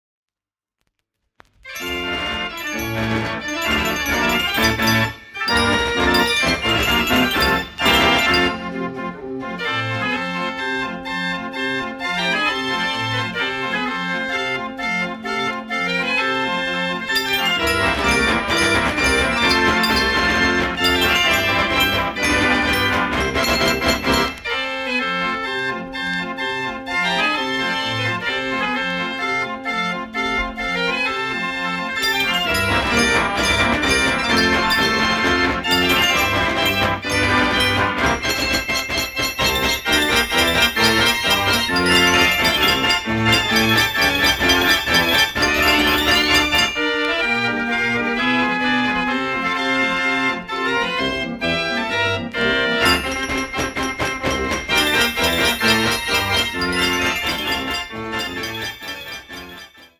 een karakteristiek Duits concertorgel
• Type: Concertorgel
• Klavier: 79 toonstufen